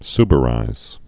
(sbə-rīz)